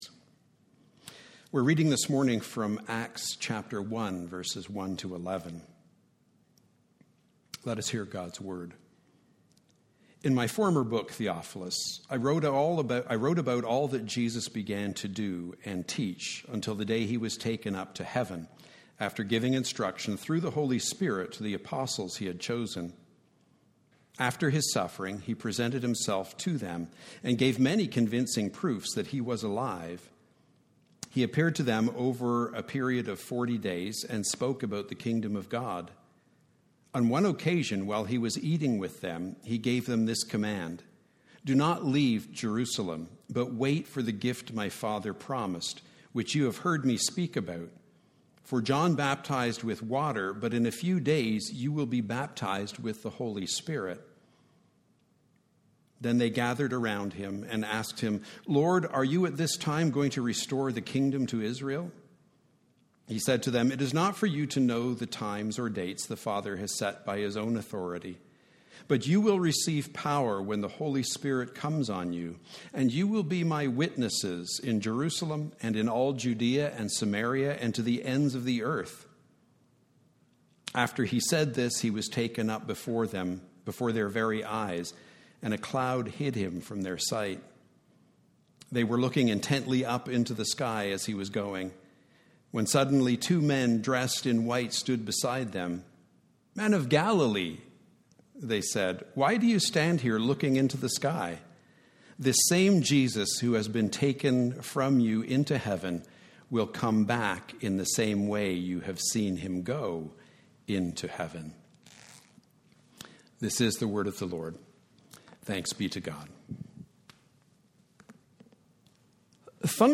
A message from the series "Summer 2020."